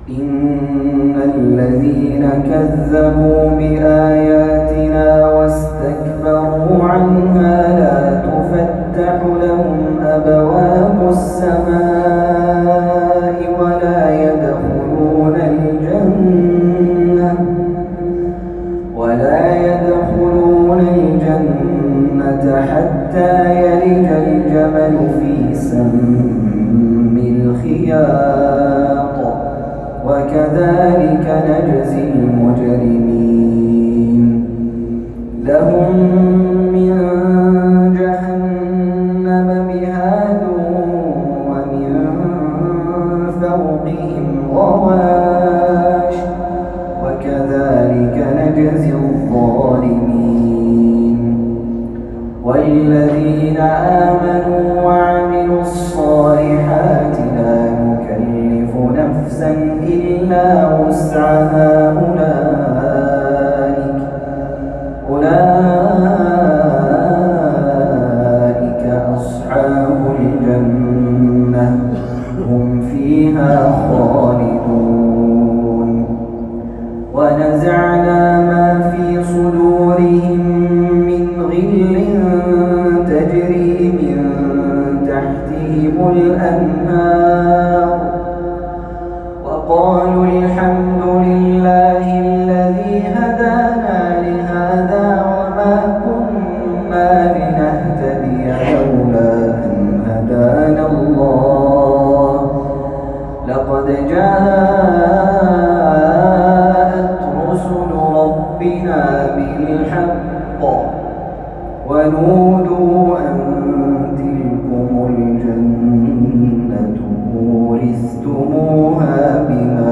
تلاوة هادئة مميزة